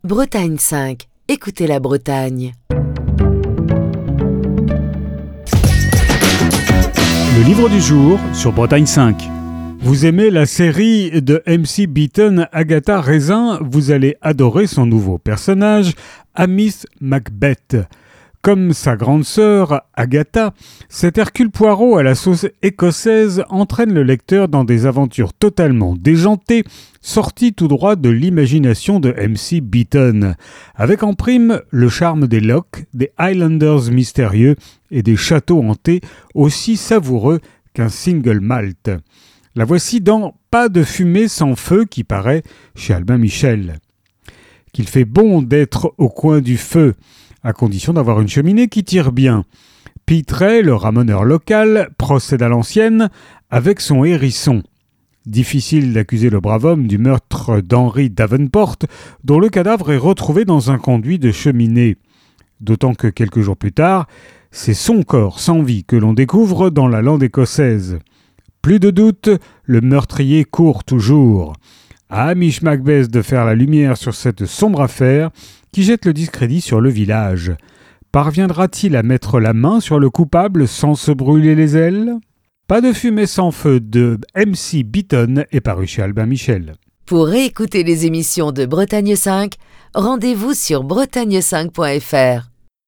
Chronique du 25 novembre 2025.